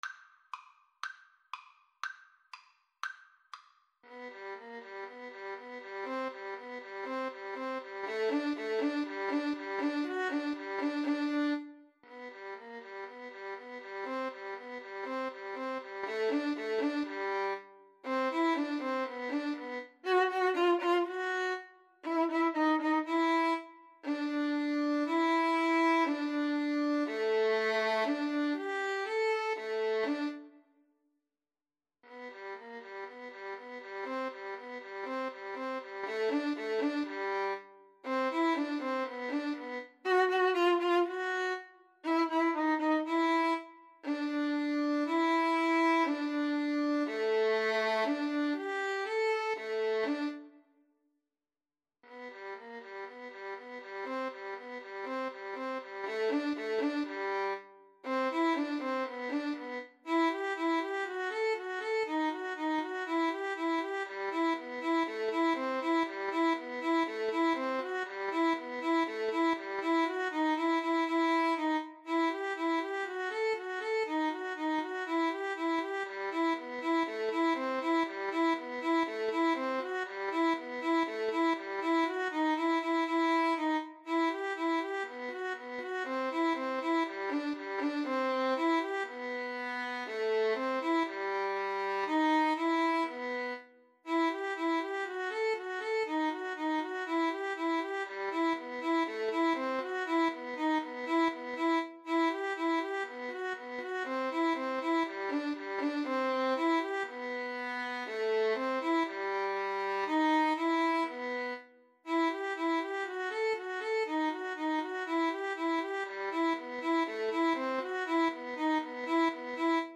Play (or use space bar on your keyboard) Pause Music Playalong - Player 1 Accompaniment reset tempo print settings full screen
G major (Sounding Pitch) (View more G major Music for Violin Duet )
Rondo - Allegro (View more music marked Allegro)
Classical (View more Classical Violin Duet Music)